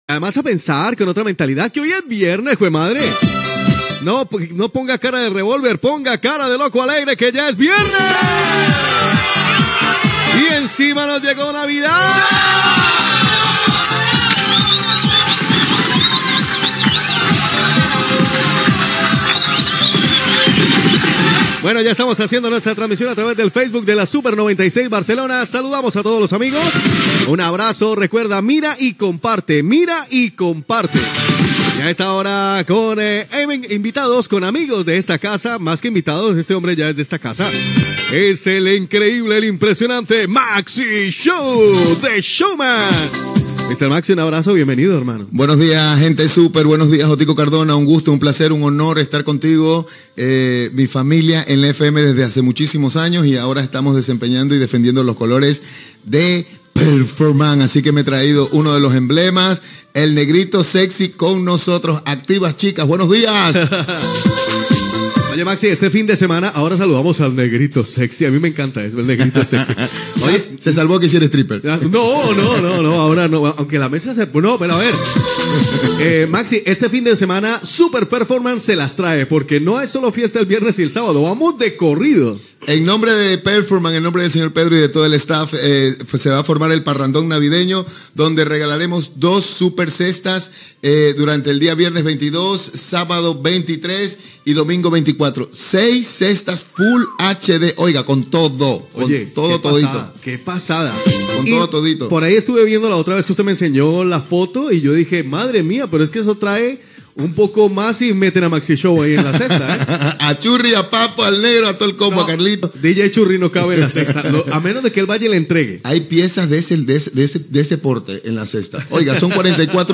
Identificació de l'emissora i anunci del sorteig de cistelles de Nadal